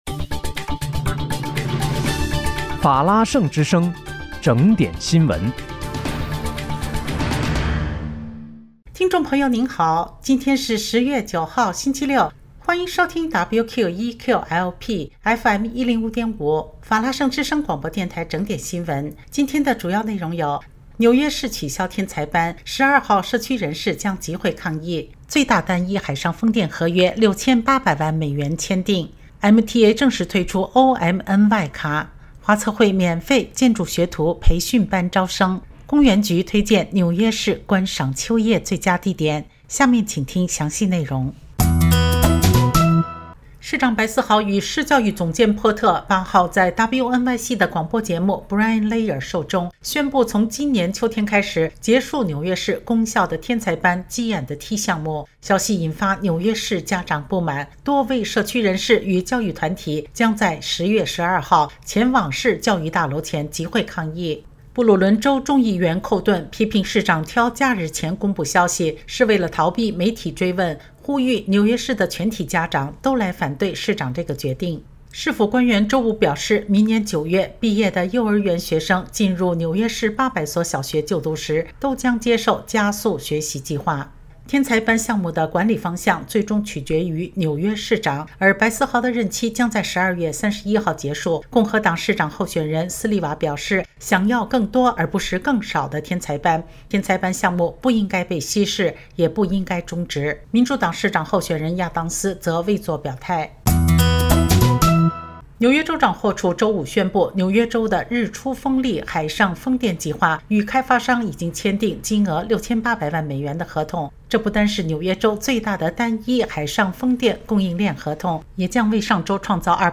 10月9日（星期六）纽约整点新闻